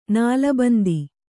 ♪ nāla bandi